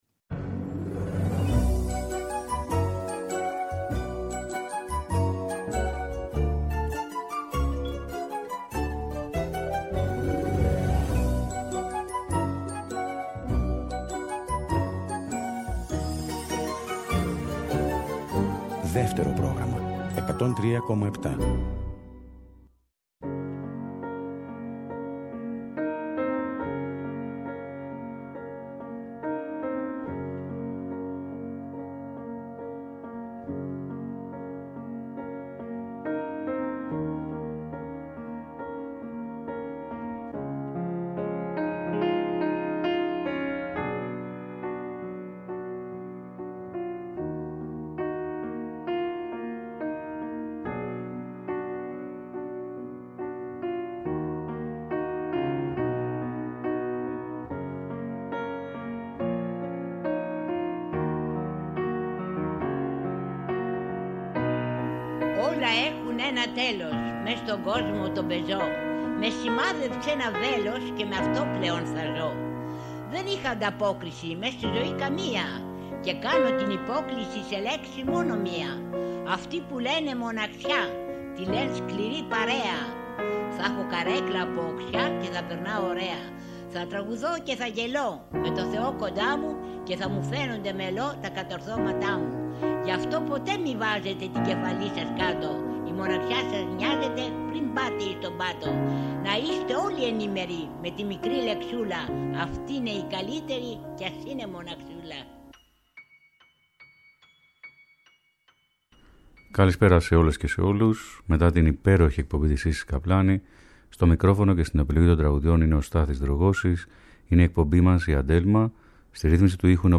Τραγούδια καλοκαιρινά δροσερά και ελληνικά